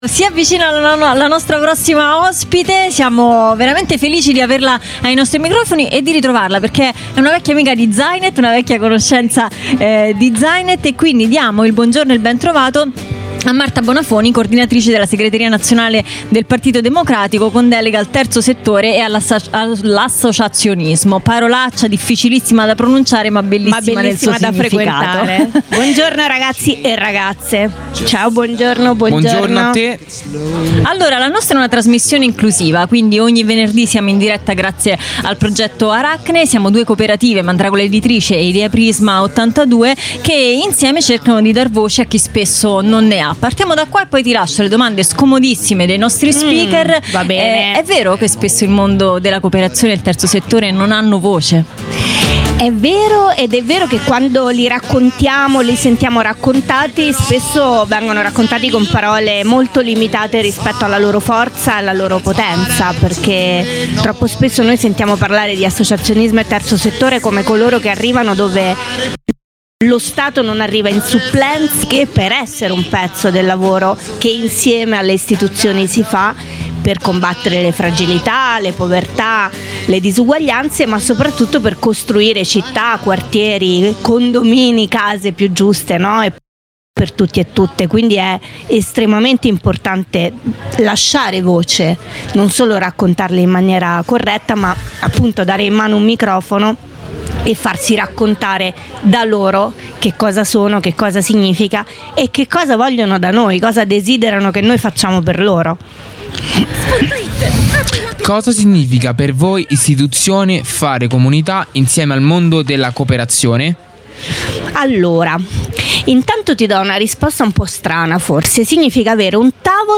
Jolly Roger - speciale GenerAction - Intervista a Marta Bonafoni